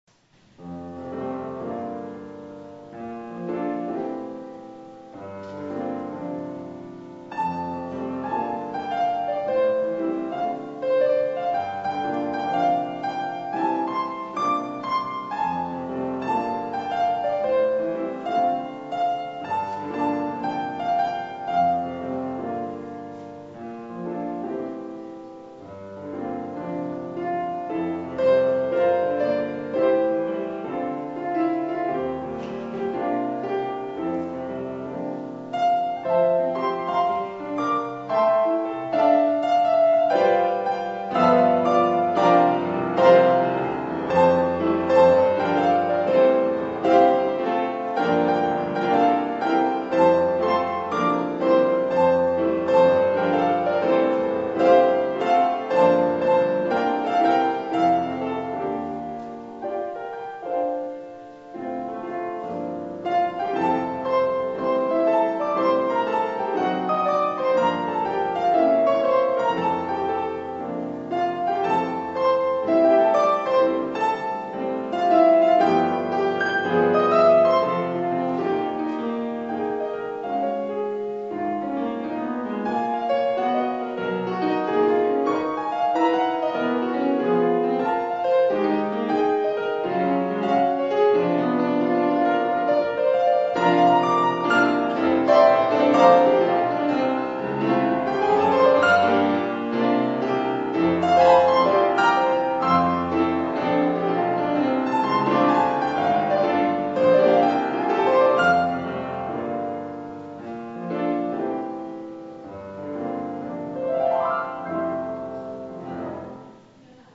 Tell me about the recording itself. Piano duets performed at the Second Reformed 2013 Christmas Concert.